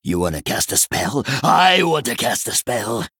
VO_KAR_009_Male_Book_Play_01.ogg